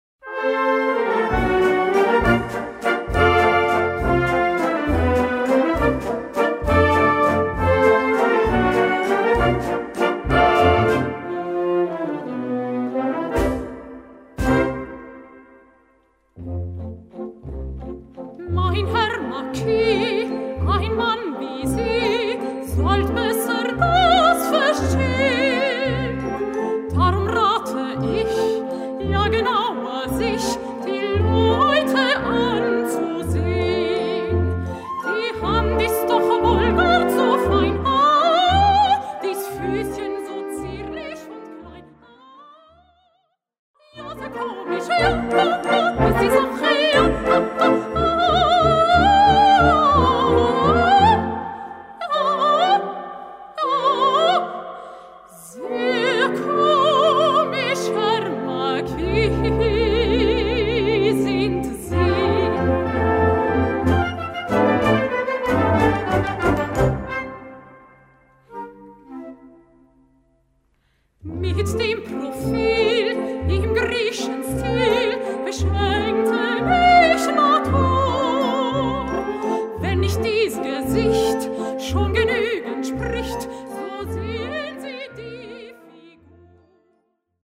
Gattung: Zwei Arien für Sopran und Blasorchester
Besetzung: Blasorchester